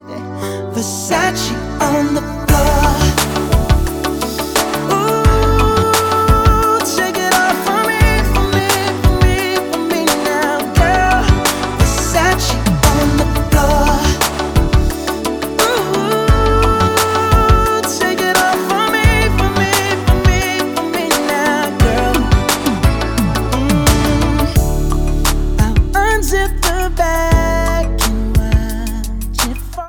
• Pop
is an R&B song reminiscent of the slow jams from the 1990s